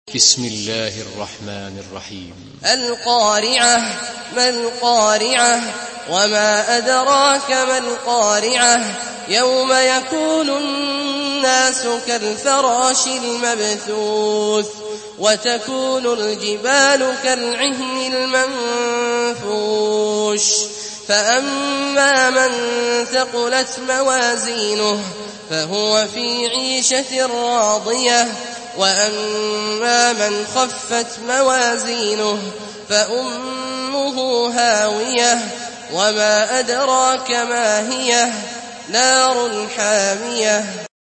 سورة القارعة MP3 بصوت عبد الله الجهني برواية حفص
مرتل حفص عن عاصم